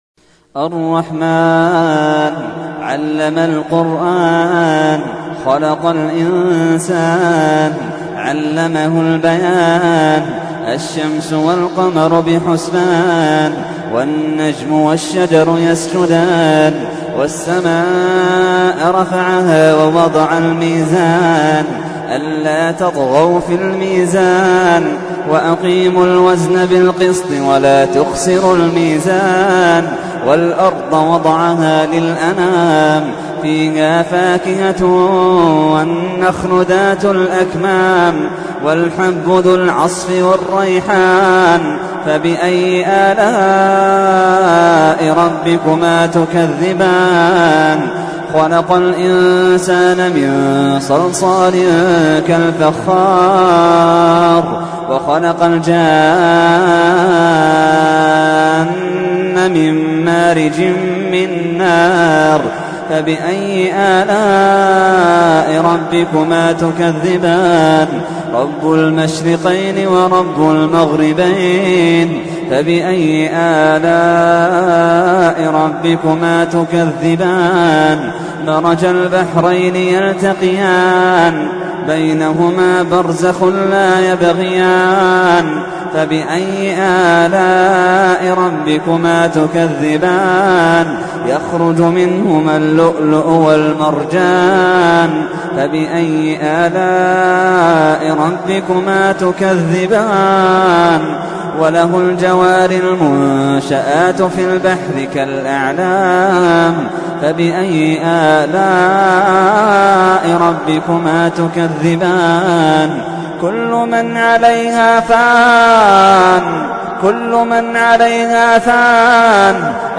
تحميل : 55. سورة الرحمن / القارئ محمد اللحيدان / القرآن الكريم / موقع يا حسين